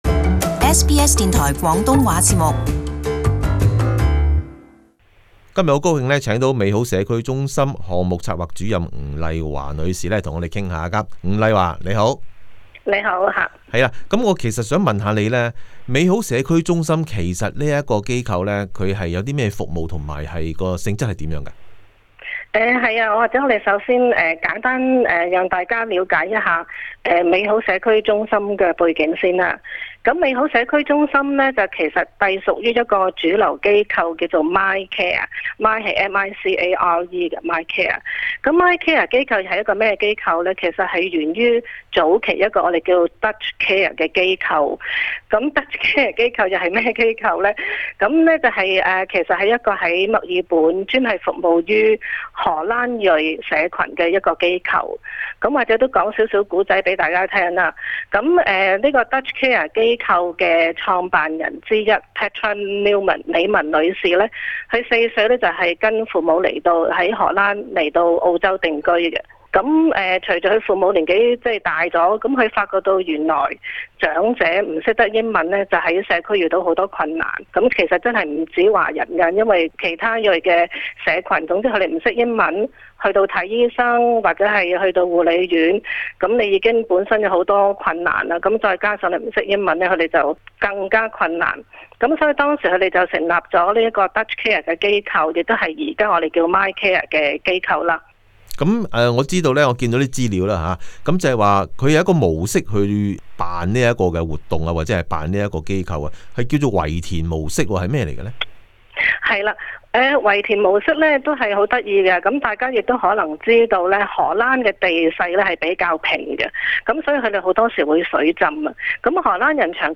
【社團專訪】墨爾本美好社區中心 - 華人長者服務